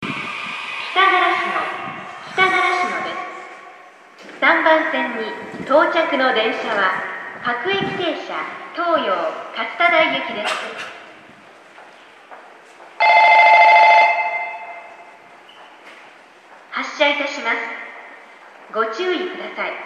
駅放送
3番線到着発車